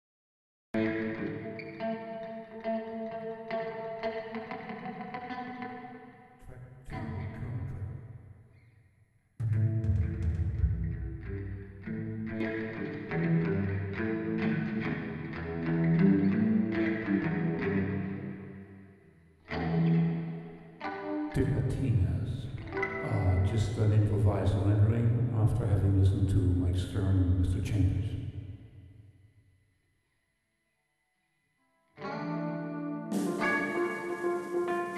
Impulse Reverb
3 short 24 bit stereo example wav files